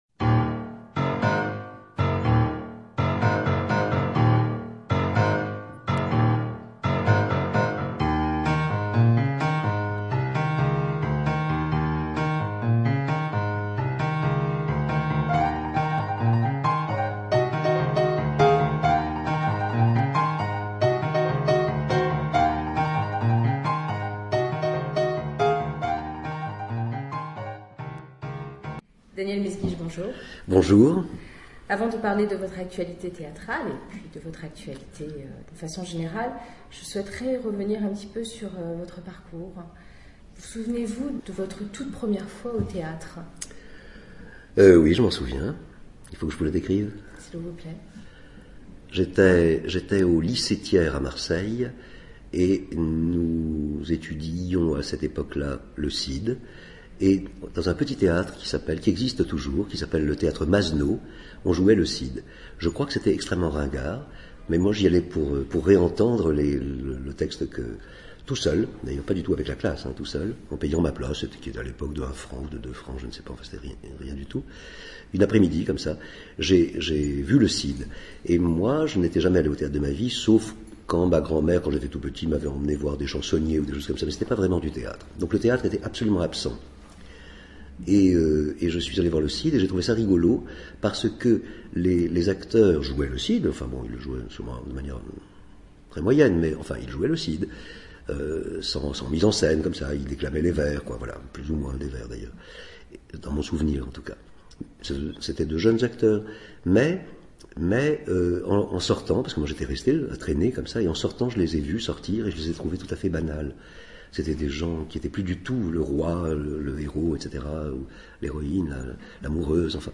Interview de Daniel Mesguich, épisode 1(1).mp3 (5.78 Mo)